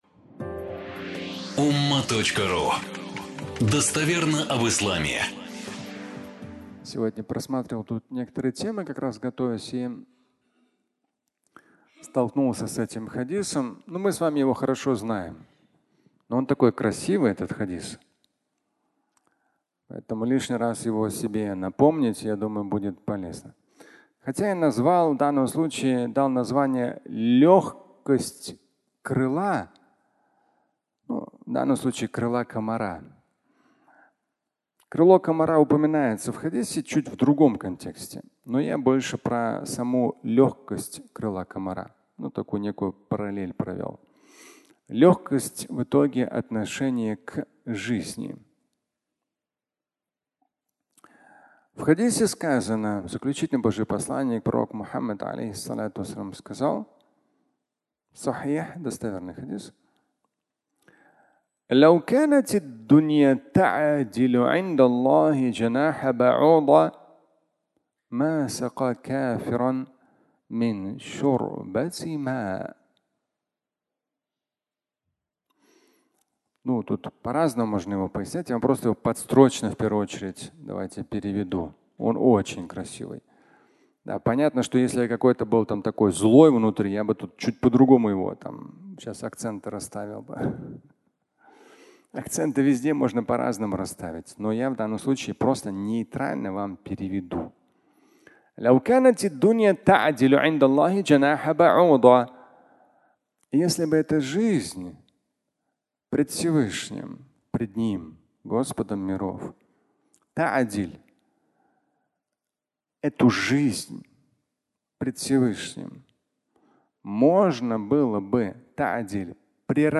Легкость крыла (аудиолекция)